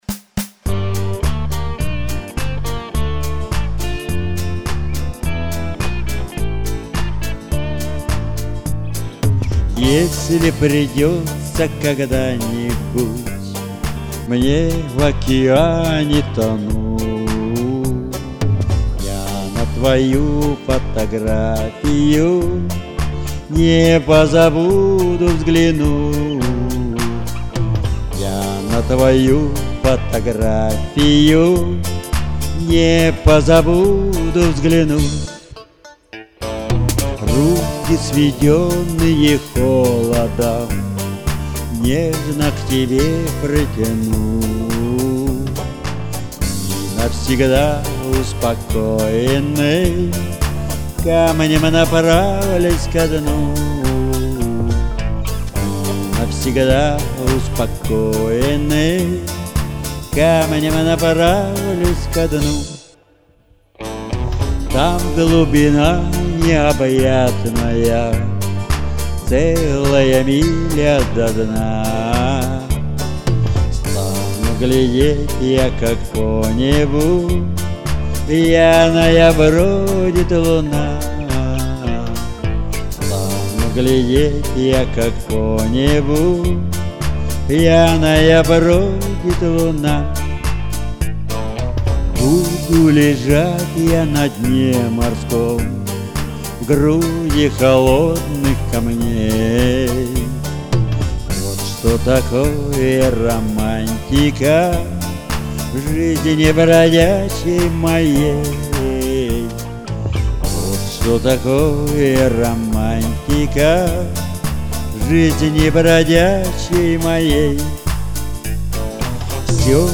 Поп (4932)